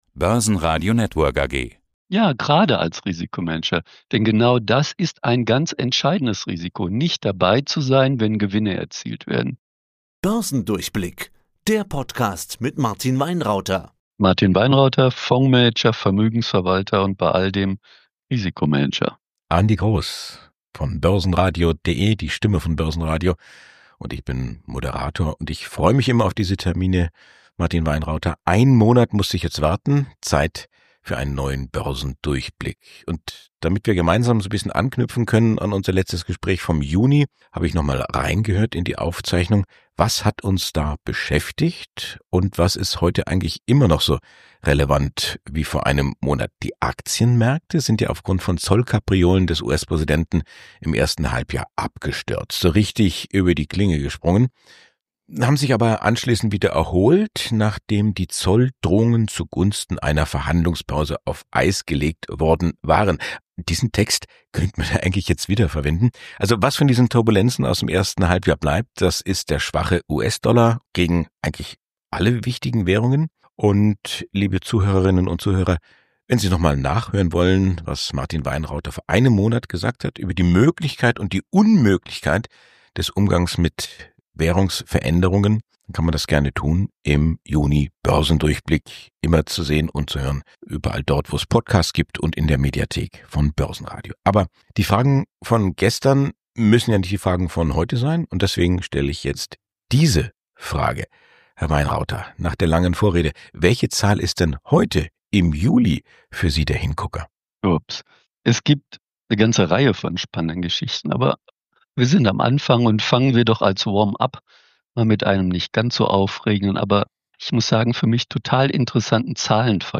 Weitere Schwerpunkte des Interviews führten zu einem Vergleich zwischen den Vor- und Nachteilen von pro- und antizyklischen Investmentstilen.